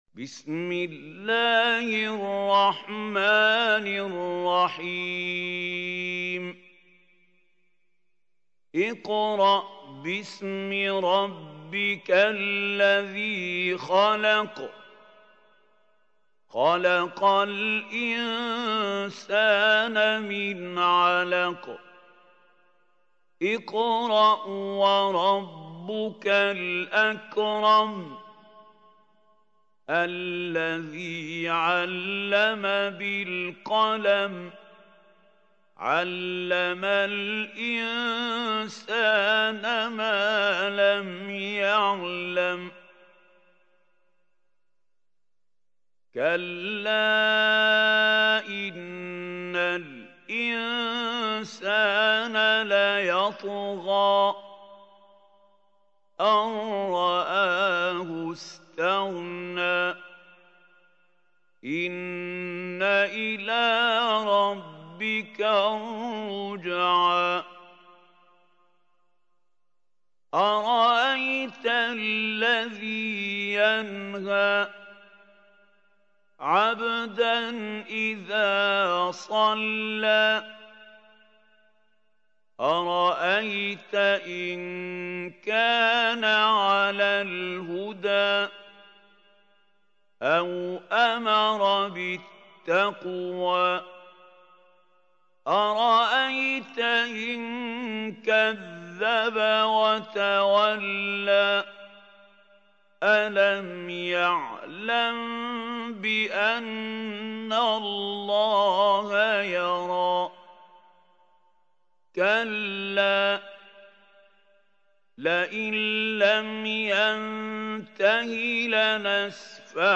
سورة العلق | القارئ محمود خليل الحصري